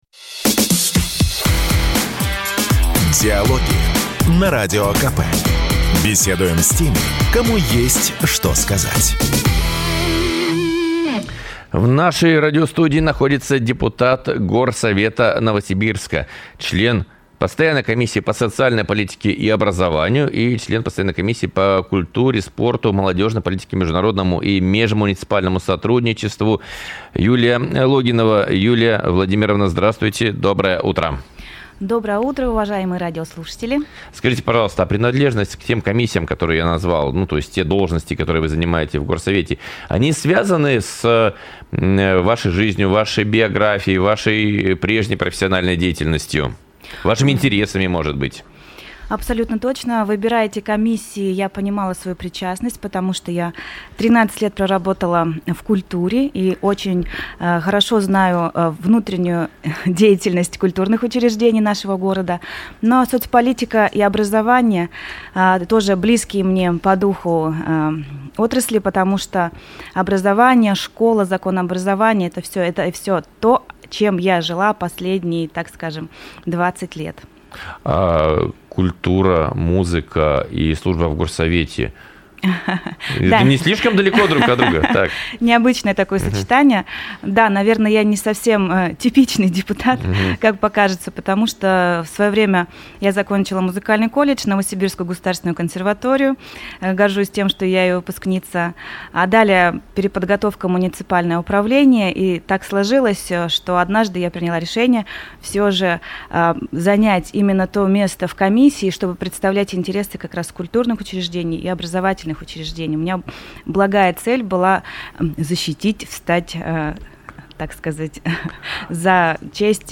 Запись программы, транслированной радио "Комсомольская правда" 01 ноября 2025 года Дата: 01.11.2025 Источник информации: радио "Комсомольская правда" Упомянутые депутаты: Логинова Юлия Владимировна Аудио: Загрузить